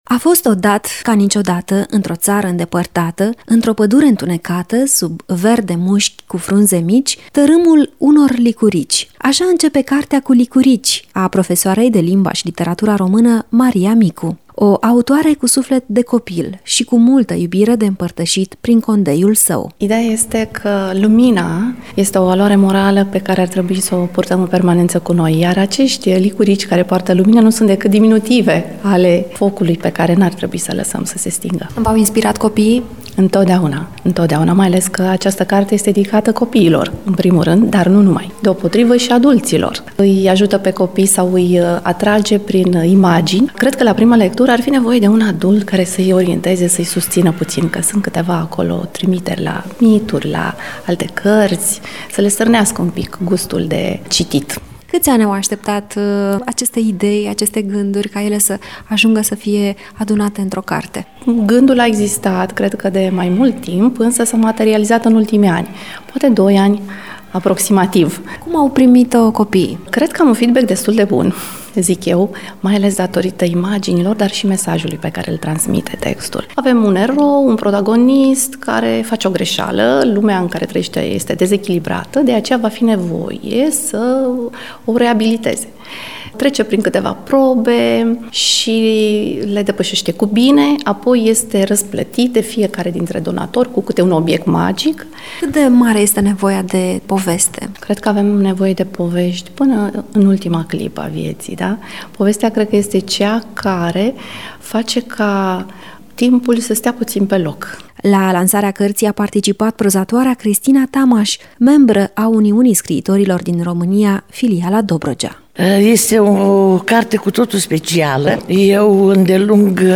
Lansare de carte